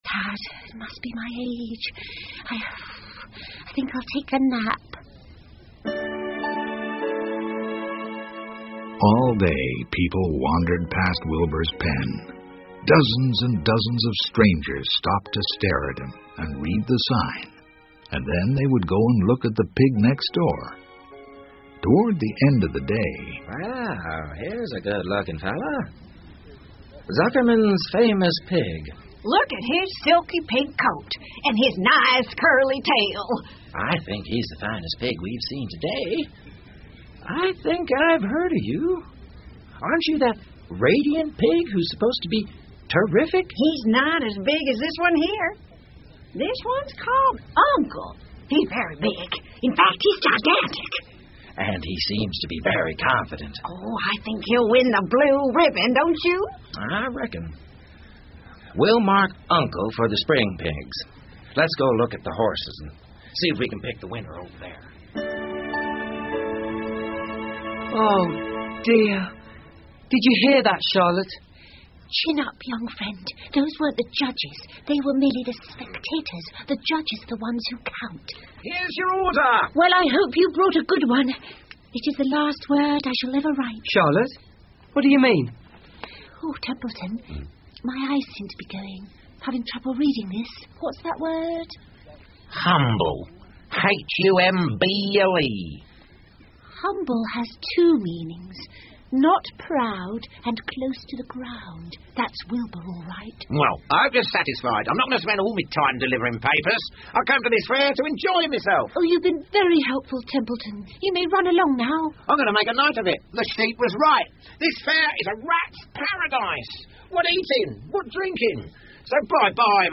夏洛的网 Charlottes Web 儿童广播剧 13 听力文件下载—在线英语听力室